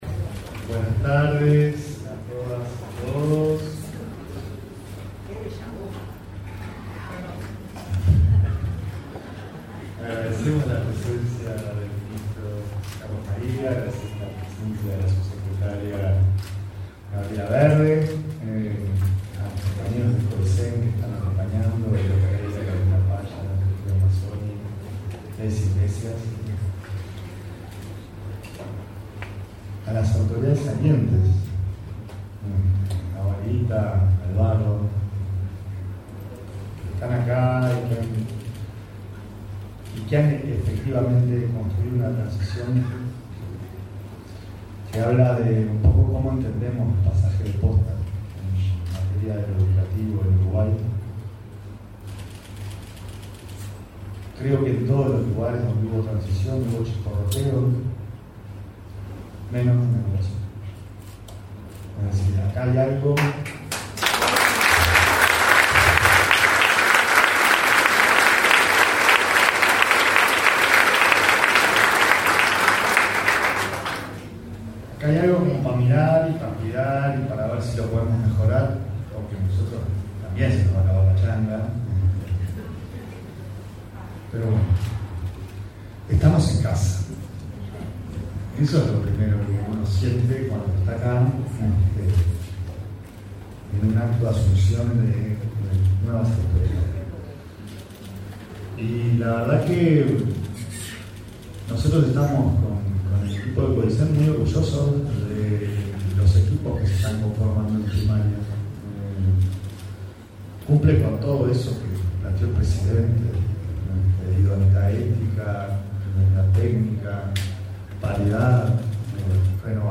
Palabras del presidente del Consejo Directivo Central, Pablo Caggiani
Palabras del presidente del Consejo Directivo Central, Pablo Caggiani 28/03/2025 Compartir Facebook X Copiar enlace WhatsApp LinkedIn Este 28 de marzo asumieron las nuevas autoridades de la Dirección General de Educación Inicial y Primaria.
El presidente del Consejo Directivo Central (Codicen), Pablo Caggiani, participó del evento.